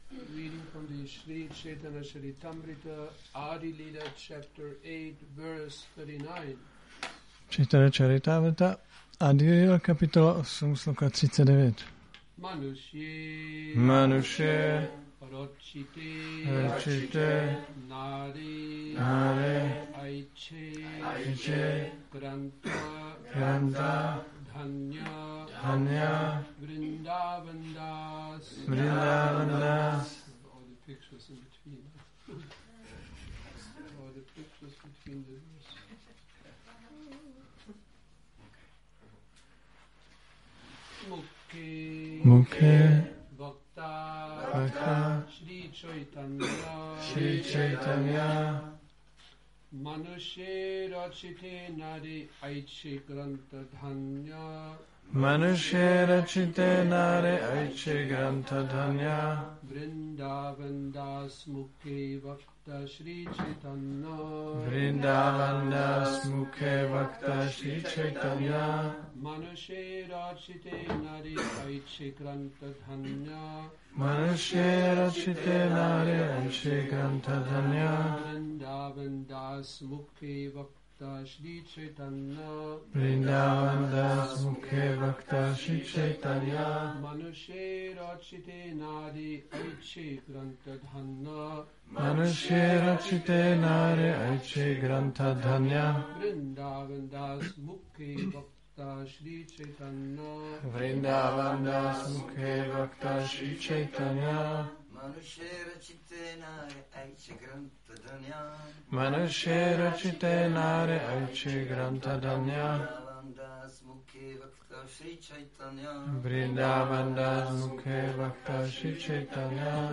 Přednáška CC-ADI-8.39 – Šrí Šrí Nitái Navadvípačandra mandir